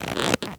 foley_leather_stretch_couch_chair_09.wav